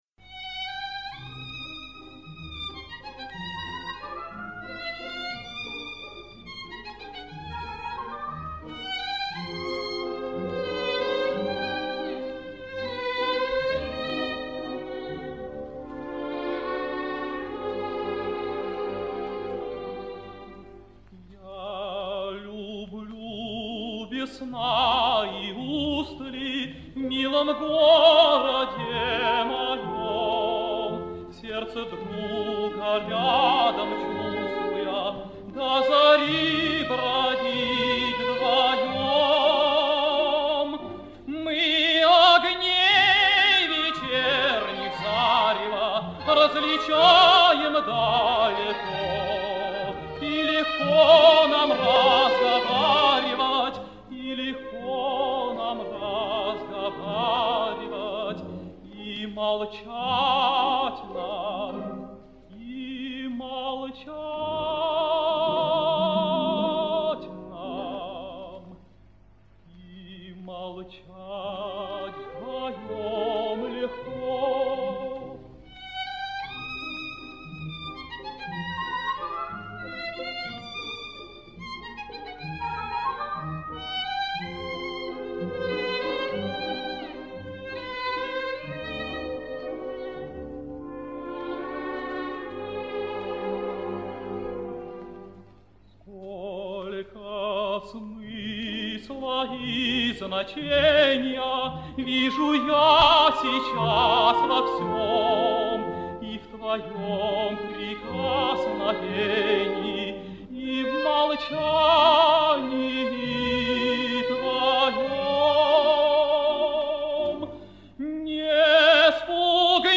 лирический тенор